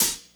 Closed Hats
Medicated Hat 17.wav